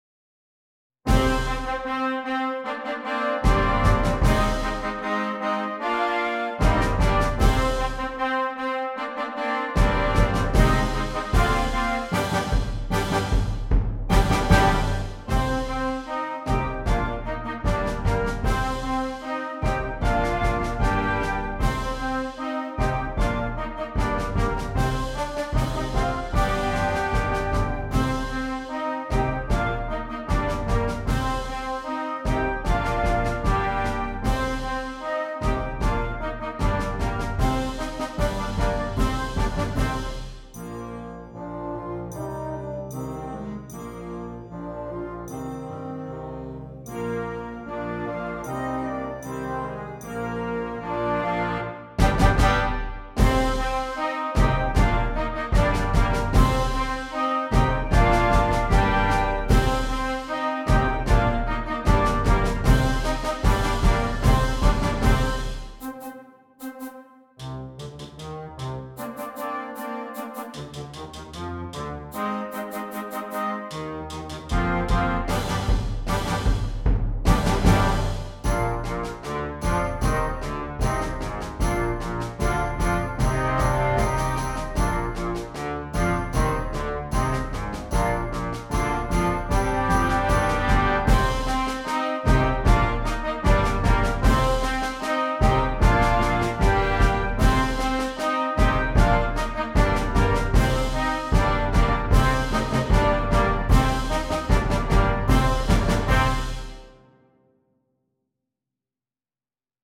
Flexible Band
fun, high energy piece